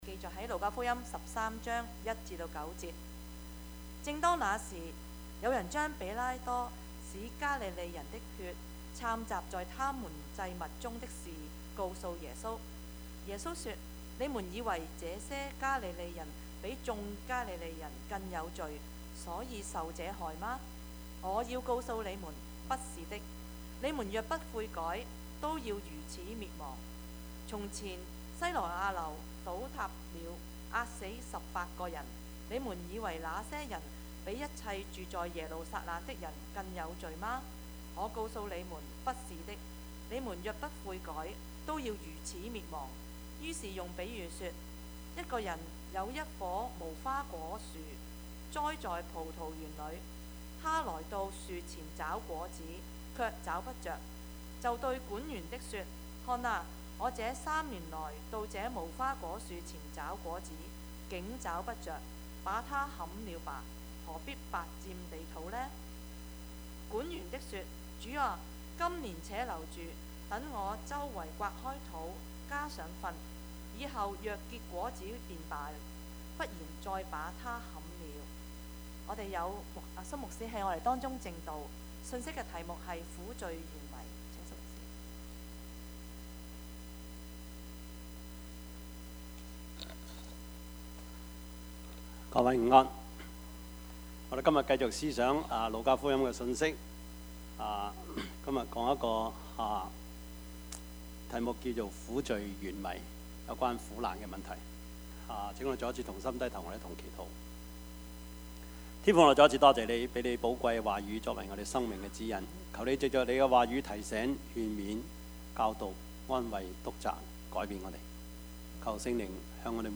Service Type: 主日崇拜
Topics: 主日證道 « 化時為機 被擄的得釋放 »